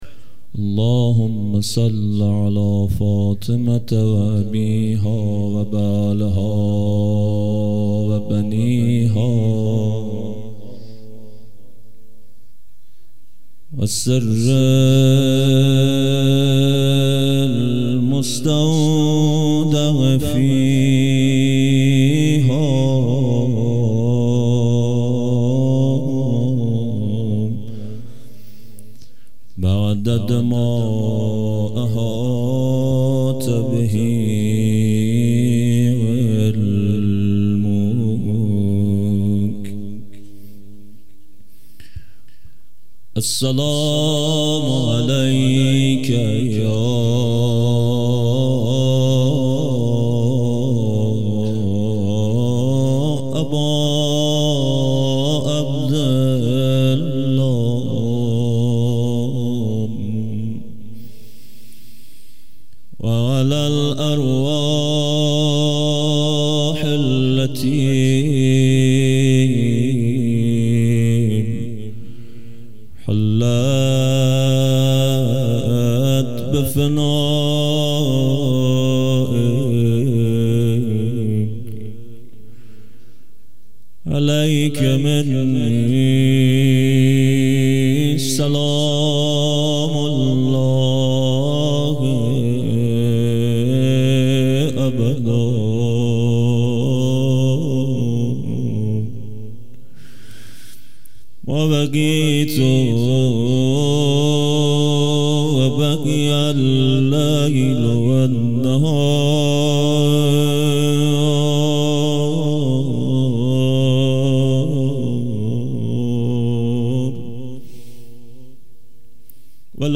خیمه گاه - هیئت آل یس عليهم السلام فاروج - روضه